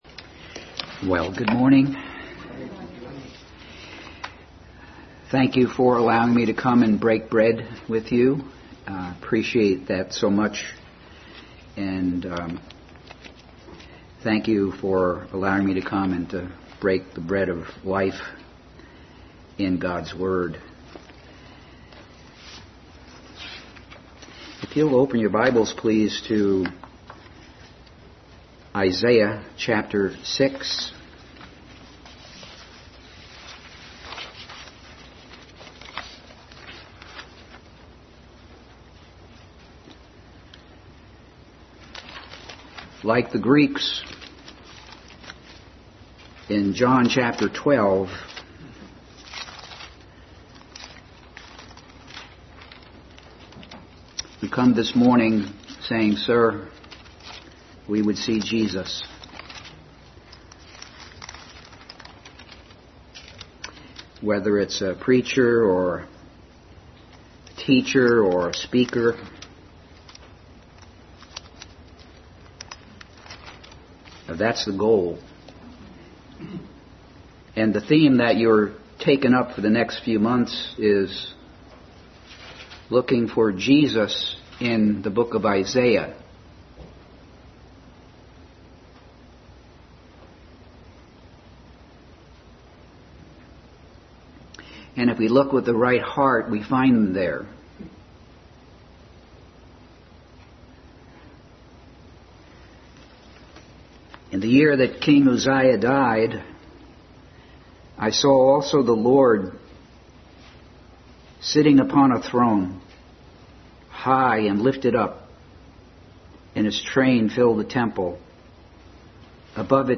Adult Sunday School Class continued study of “Jesus in Isaiah”.
Acts 28:25-28 Service Type: Sunday School Adult Sunday School Class continued study of “Jesus in Isaiah”.